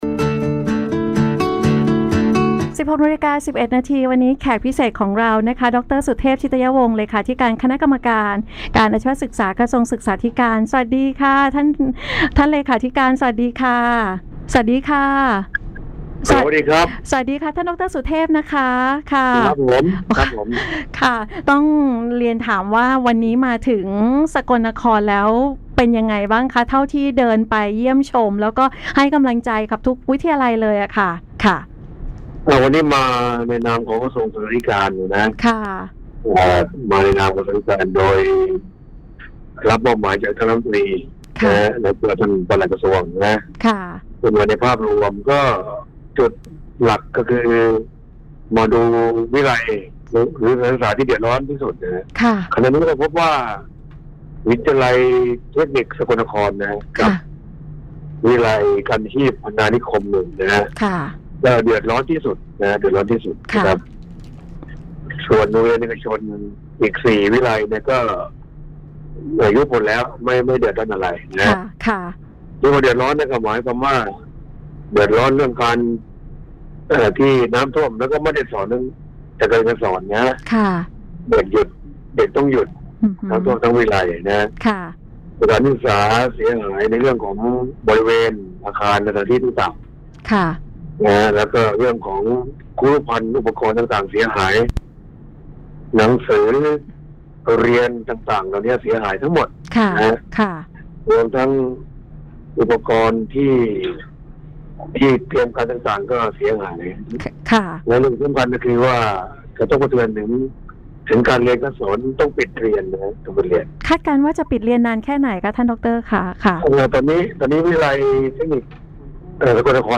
เสียงสัมภาษณ์